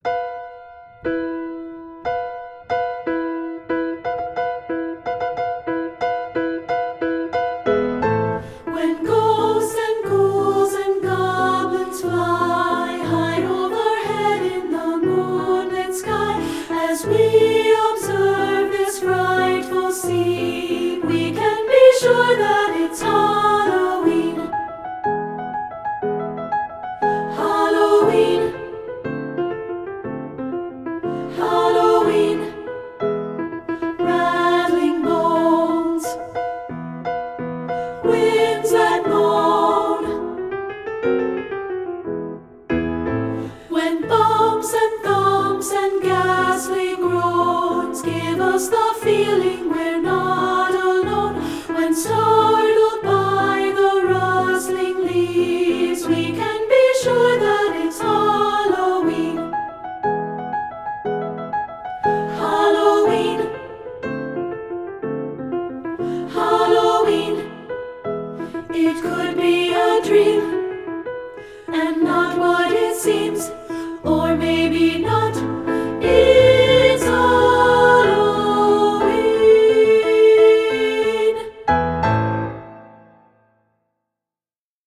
Voicing: Unison|2-Part and Piano